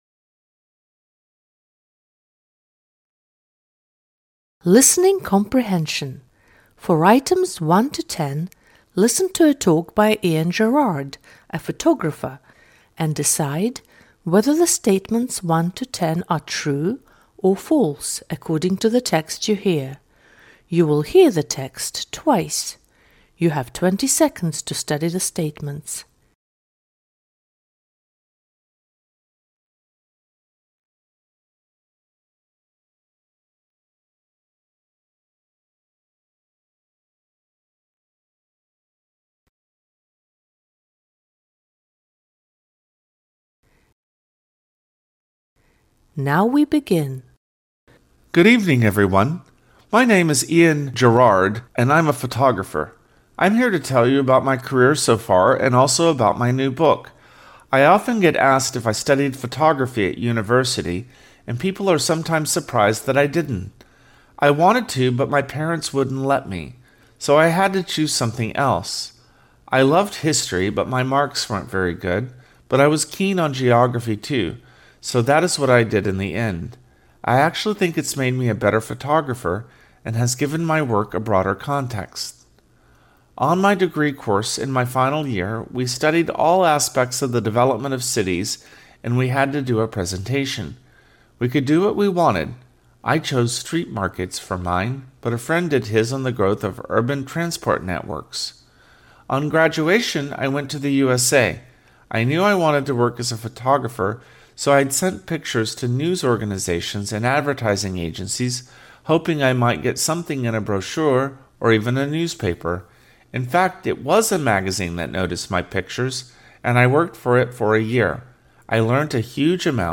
You will hear the text twice.